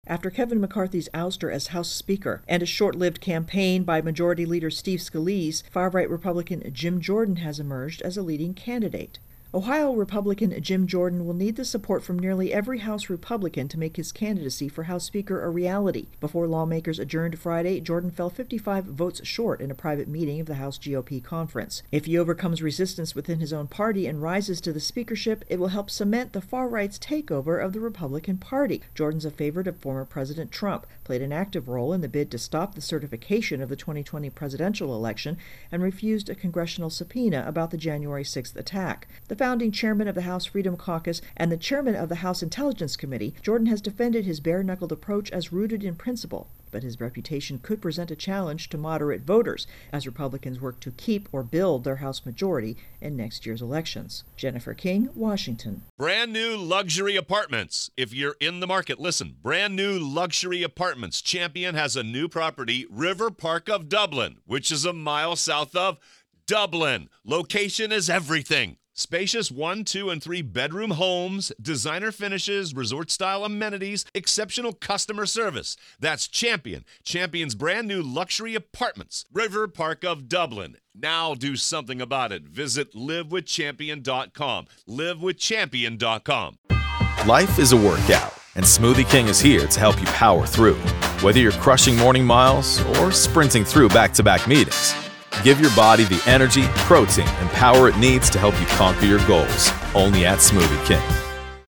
reports on Congress Jordan - House GOP Speaker's contest